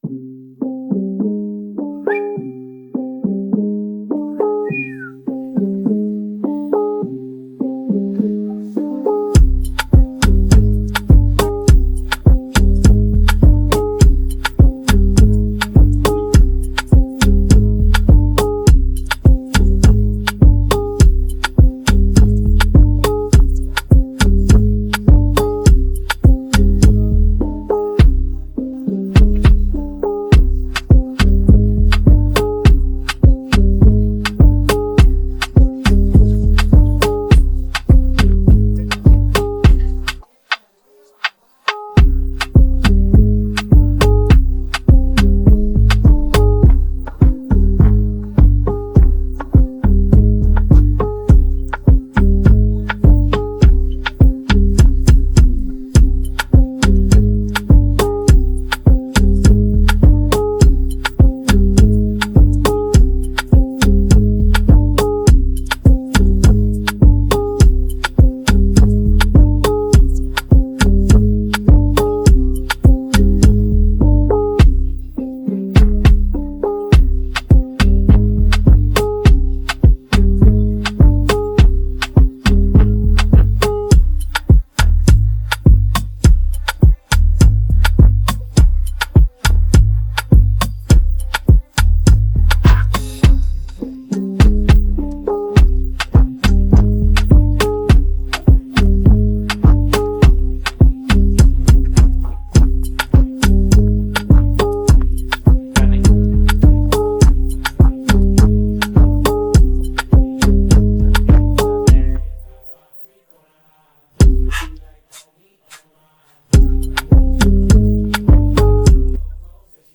Afro dancehallDancehallReggae